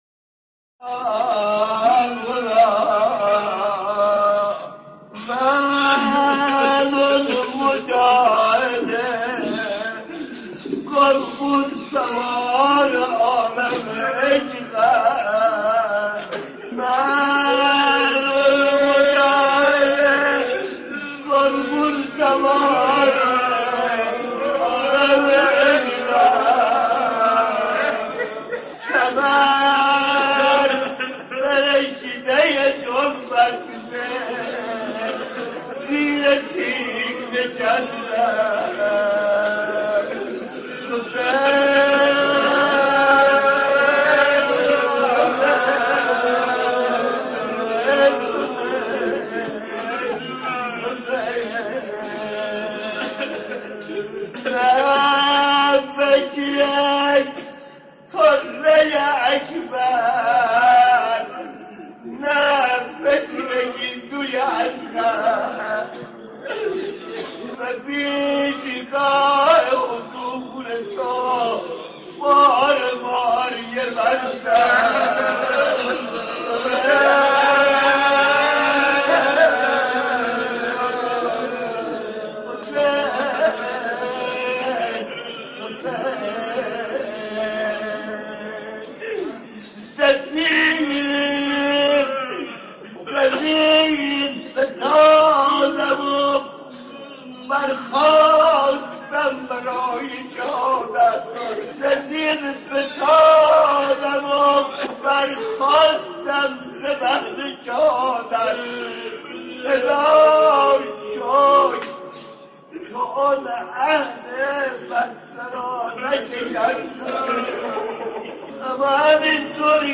روضه - حضرت علی اصغر